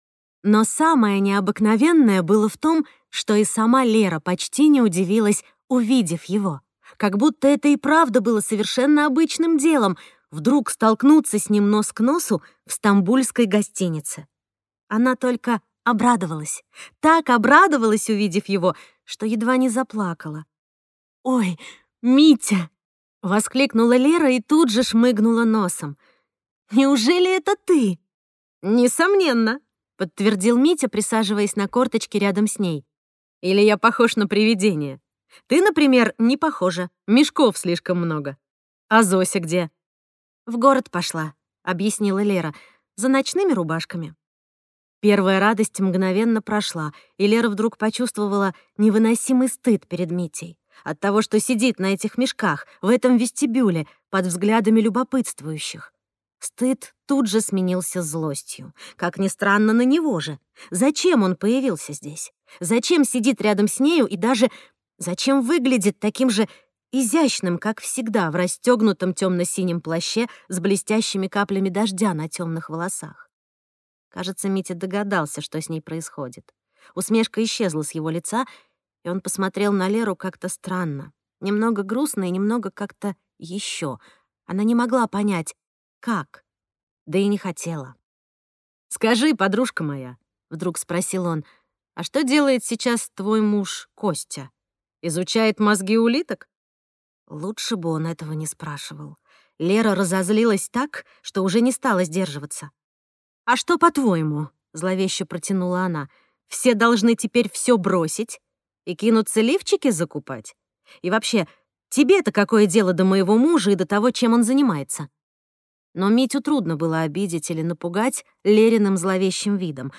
Аудиокнига Слабости сильной женщины | Библиотека аудиокниг